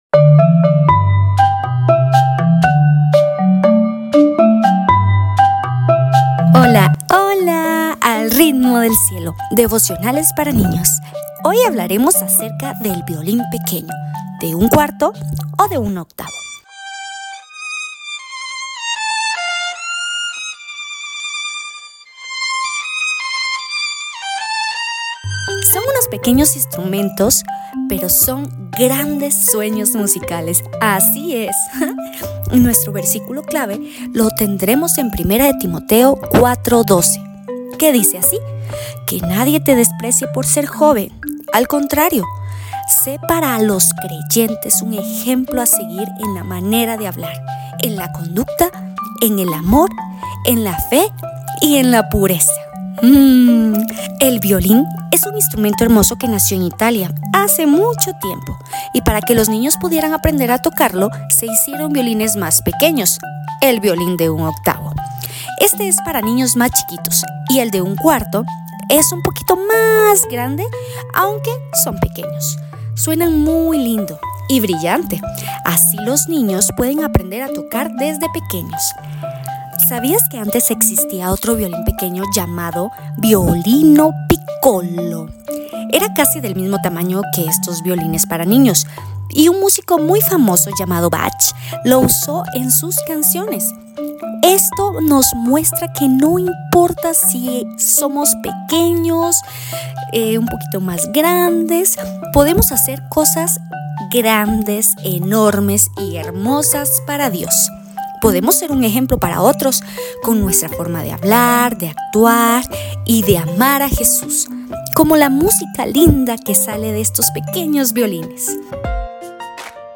– Devocionales para Niños